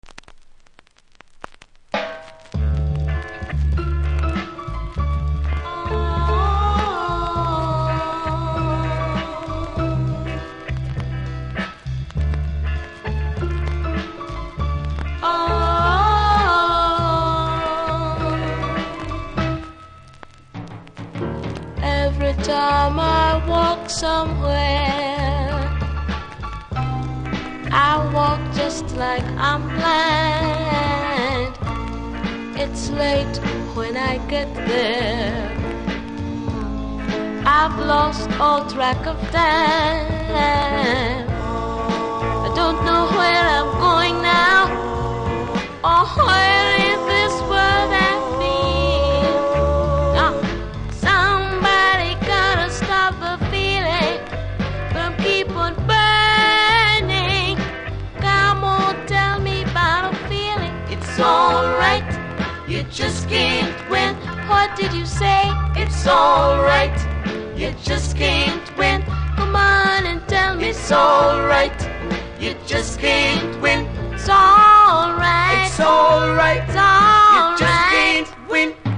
序盤ノイズありますので試聴で確認下さい。